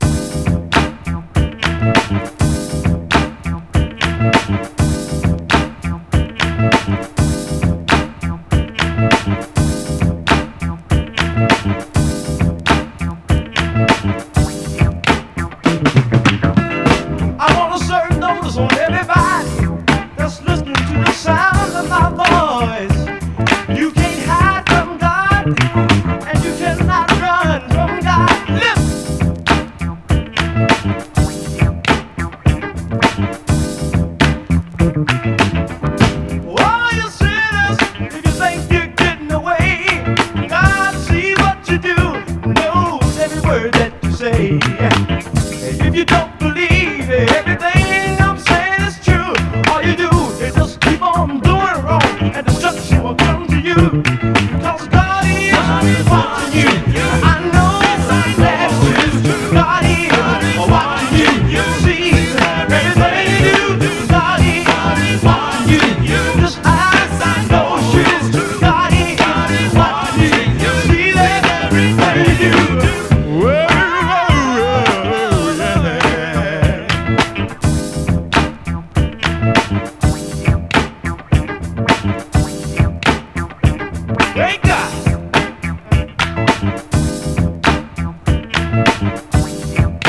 ピュンピュン音入りのラテン風味アーバン・ディスコ・ブギーがナイス！